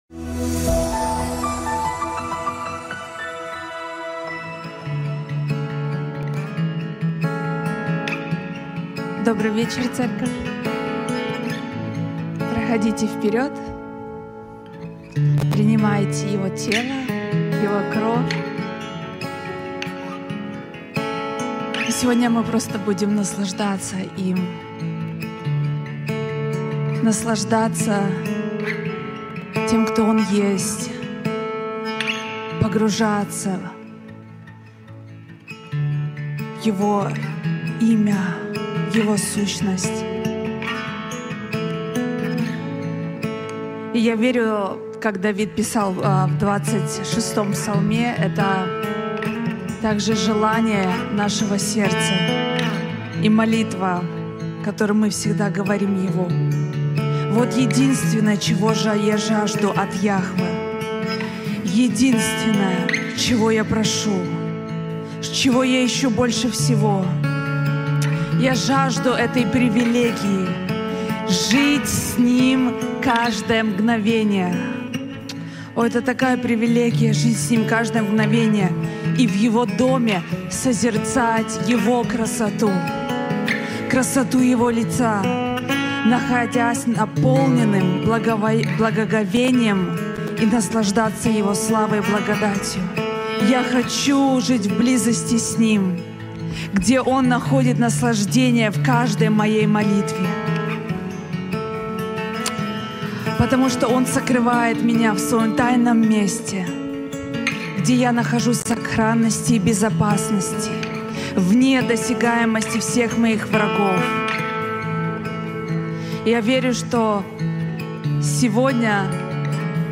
Transformation Center Молитва 814
00:15 Transformation Center Молитва 814 МОЛИТВА_814.mp3 Категория : Молитвы Центра